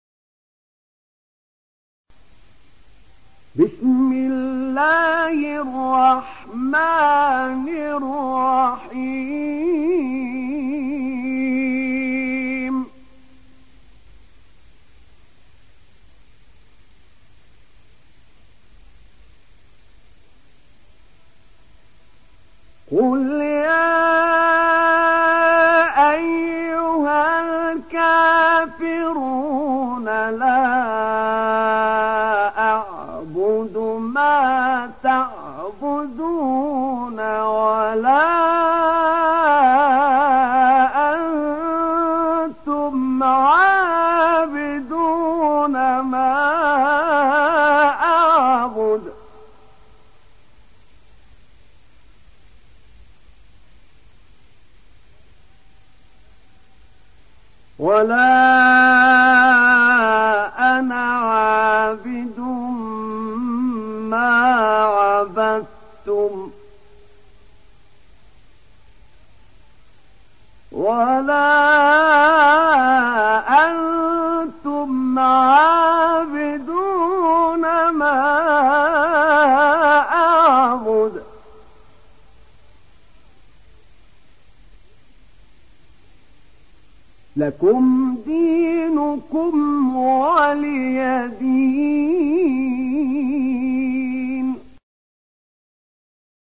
Surah আল-কাফিরূন MP3 in the Voice of Ahmed Naina Mujawwad in Hafs Narration
Surah আল-কাফিরূন MP3 by Ahmed Naina Mujawwad in Hafs An Asim narration.